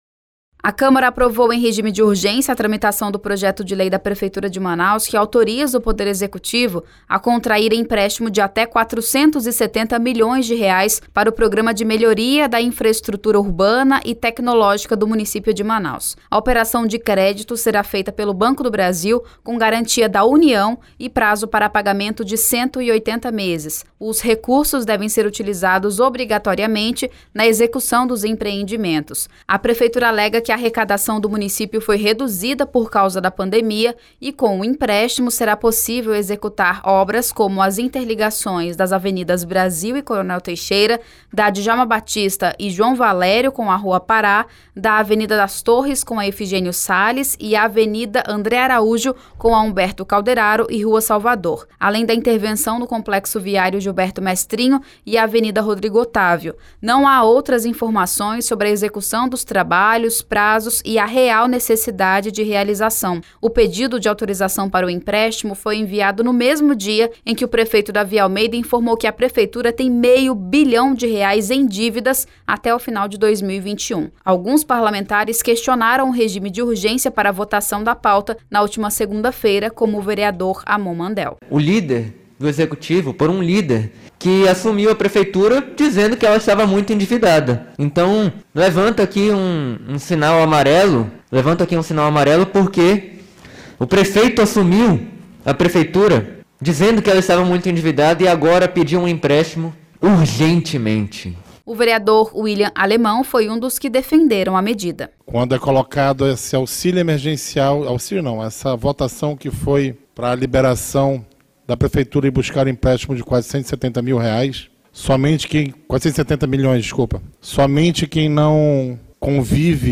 Entenda na reportagem: